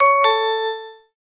13_Door_Bell.ogg